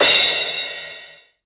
• Small Reverb Crash Sound Sample D# Key 22.wav
Royality free drum crash sound tuned to the D# note. Loudest frequency: 5412Hz
small-reverb-crash-sound-sample-d-sharp-key-22-p0z.wav